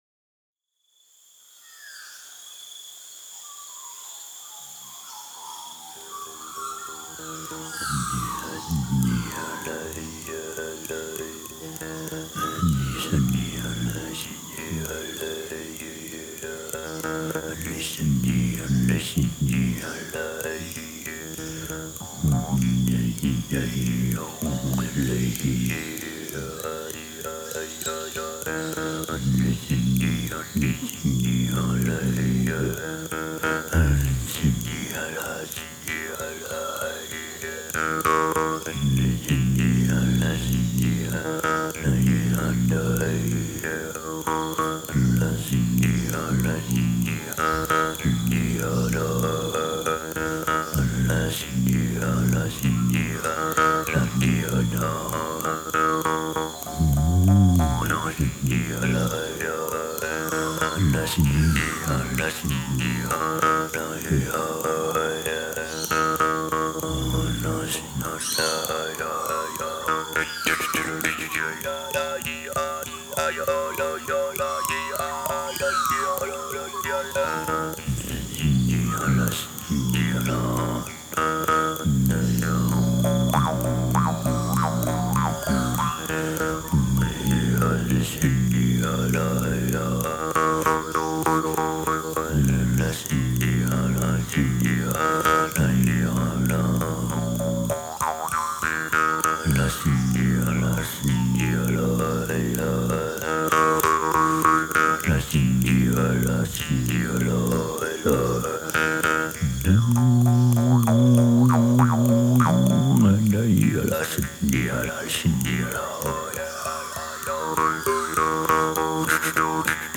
abstract, harmonic vocal pieces
Earthharp, birdsong, insects, wind, thunder and rain.